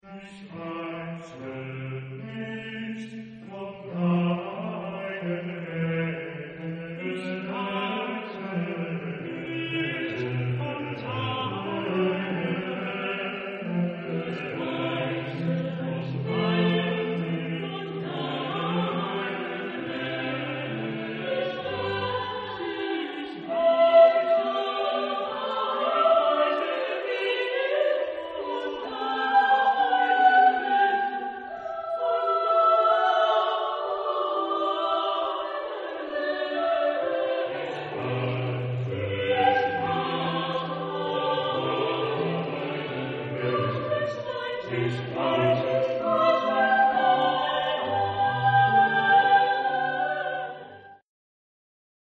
Genre-Style-Forme : Sacré ; Motet ; Psaume
Type de choeur : SATB  (4 voix mixtes )
Tonalité : do majeur